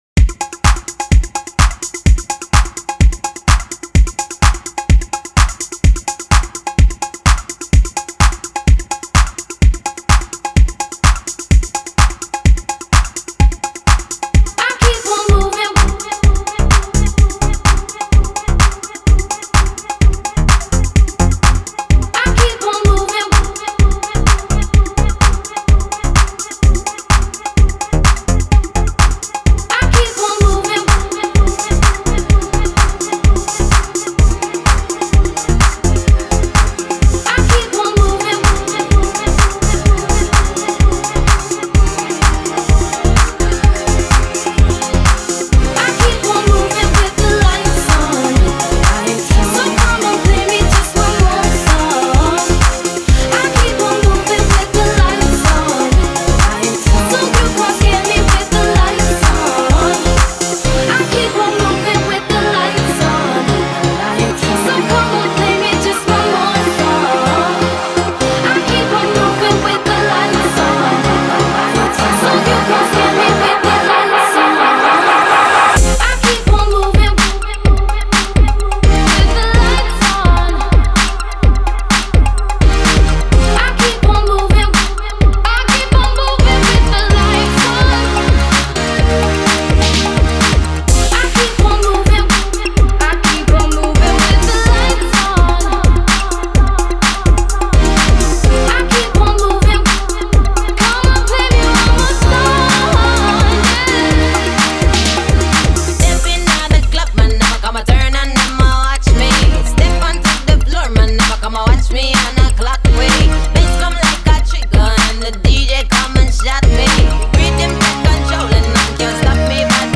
old school electro flavor